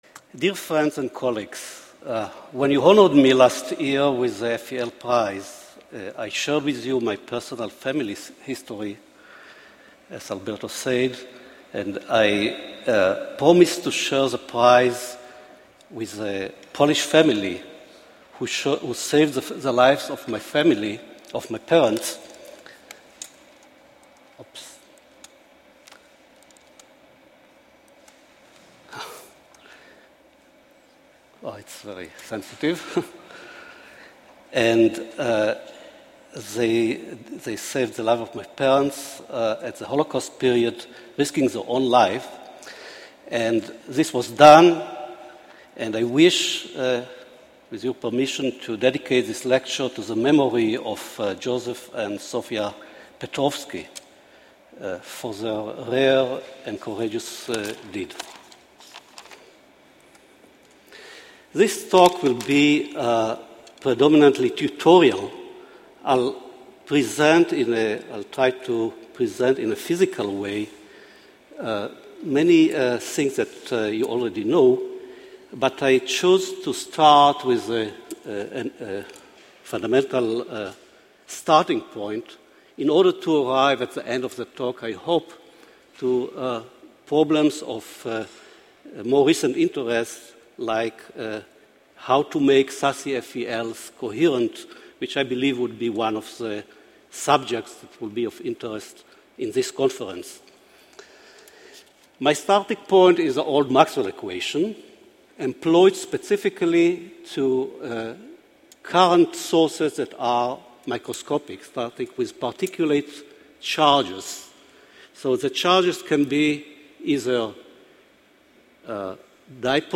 FEL Prize Lecture: Coherent Electron-Beam Radiation Sources and FELs: A Theoretical Overview